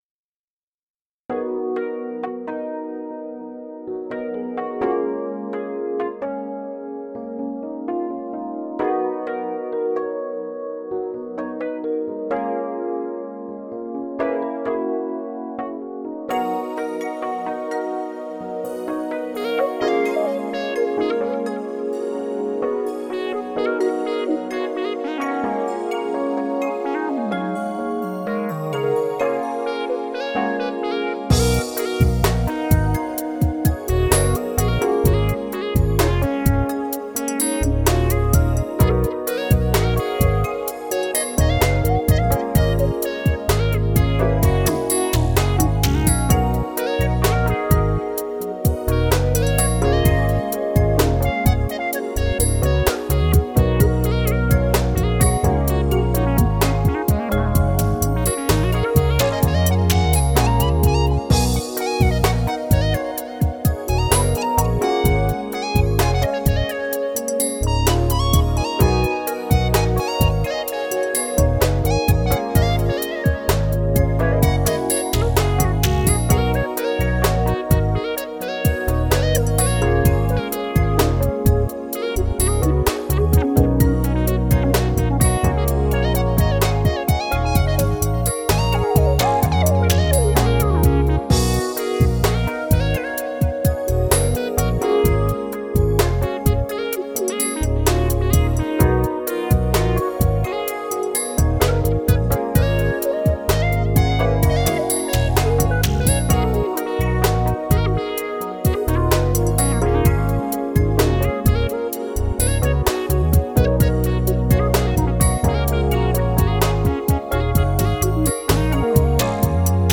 Can't remember if I've shared it or not, but I sequenced it first in a dream, then remembered all the patches i used, and about 2 days later when I remembered where the drum patches i wanted were, this happened. it's simply called dream sequence idea.